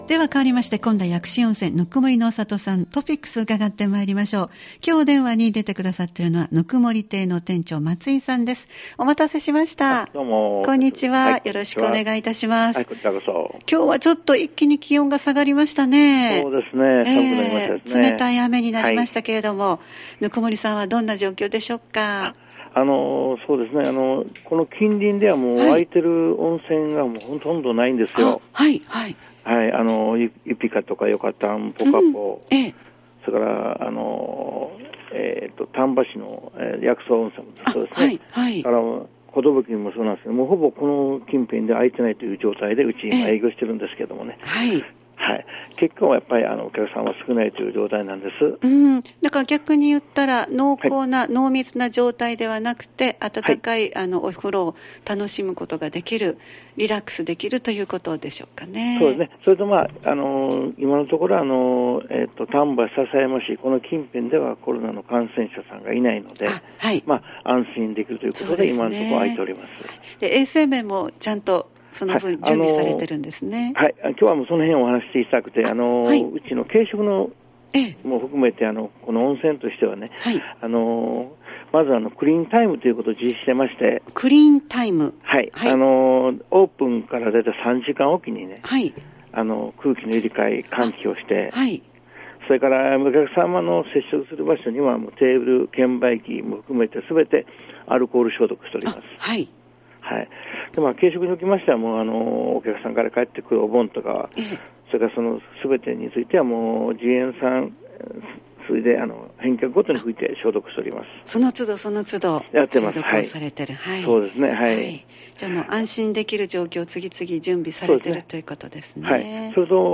毎週月曜日16時台は丹波篠山市にある「こんだ薬師温泉ぬくもりの郷」に電話をつないで、スタッフの方からイベントや企画、タイムリーな情報を紹介してもらっています。